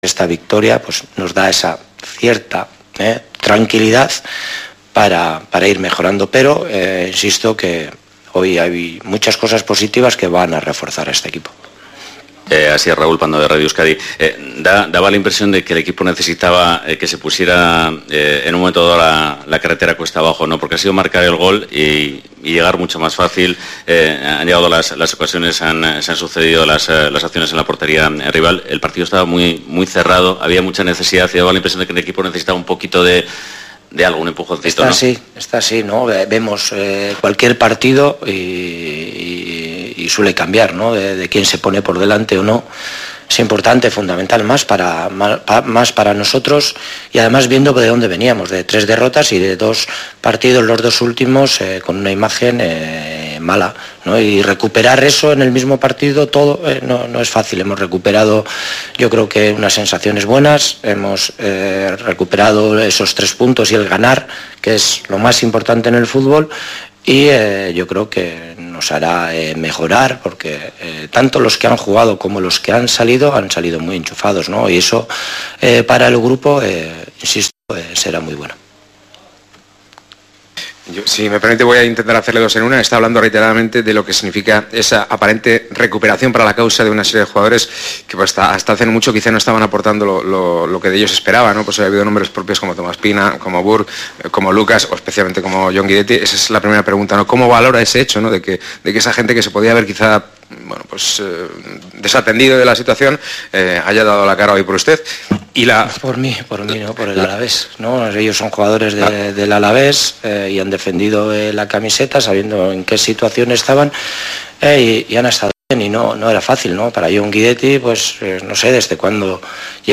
Barca-Baskonia jornada 2 liga ACB 2019-20 retransmisión Radio Vitoria